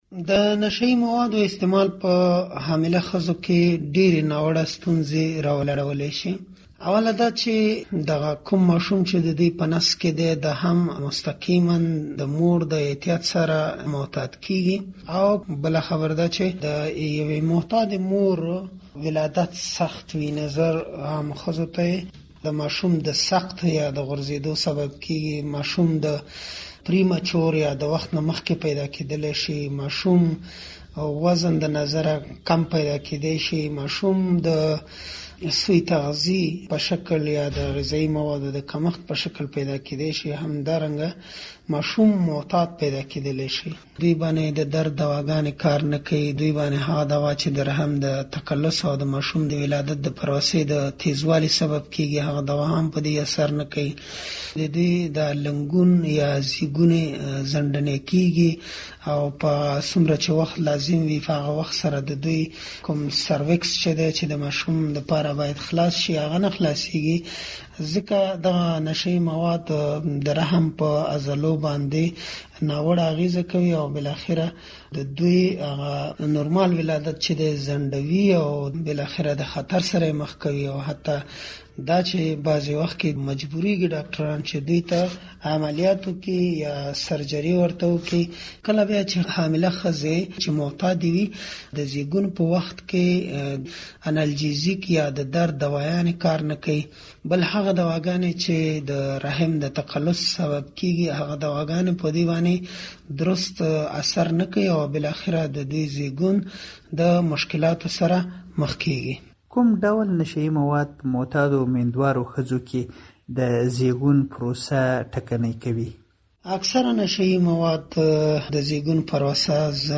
دا مرکه د لاندې غږيز فايل په کېکاږلو اورېدلی شئ: